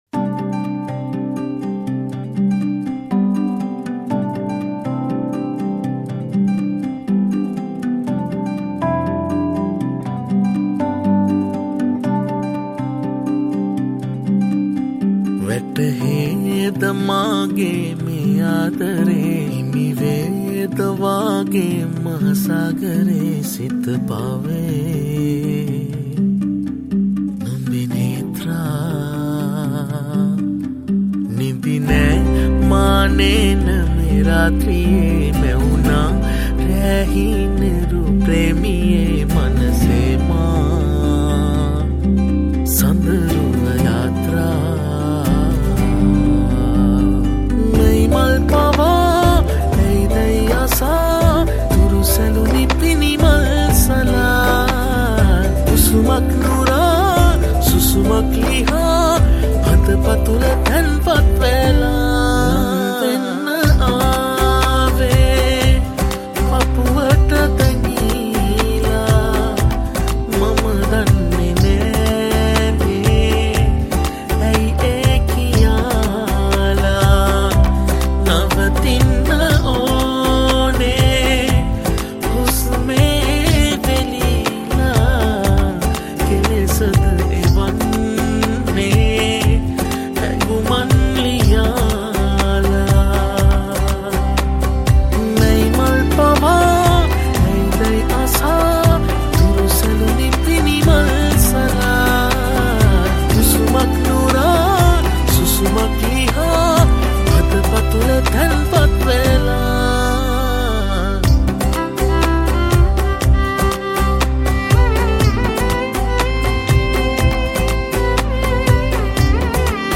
Violin
Keys
Guitars